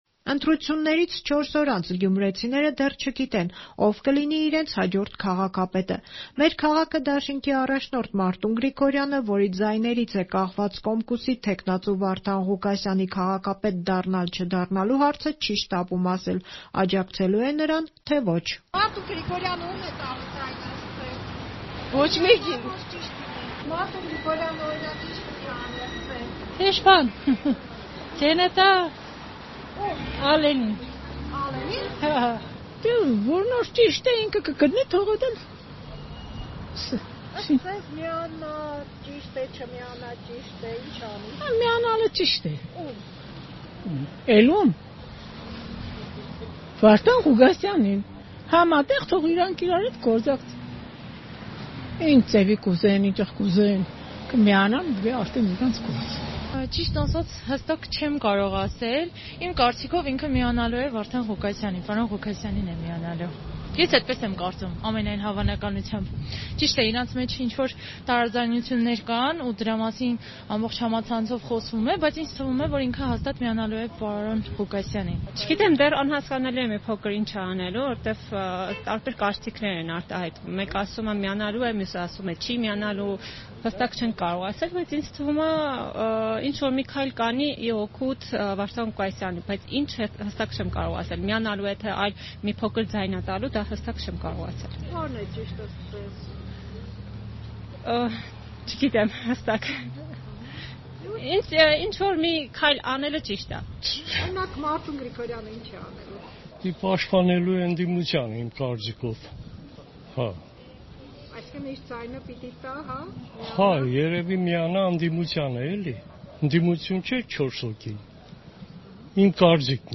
Ո՞վ կլինի քաղաքապետը. հարցում Գյումրիում